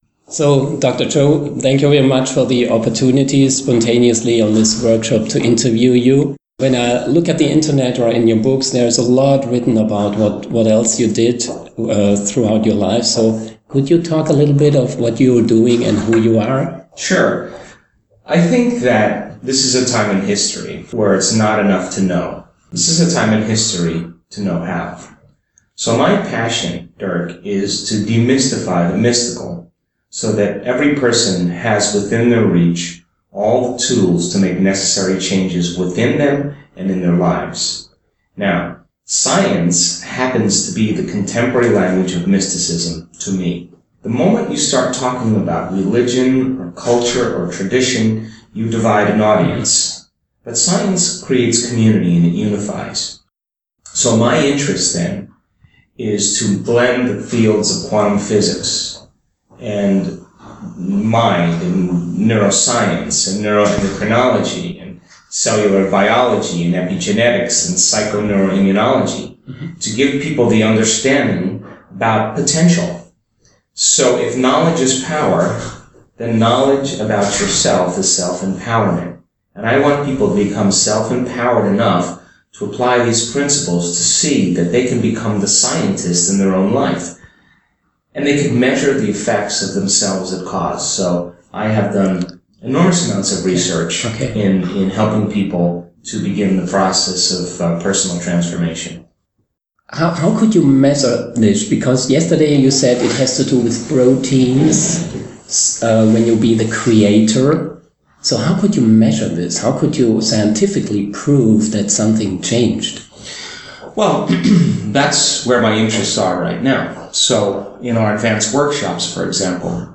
Interview mit Dr. Joe Dispenza zu Stress, Ängsten, Persönlichkeitsentwicklung
interview-Joe-Dispenza2017fertig.mp3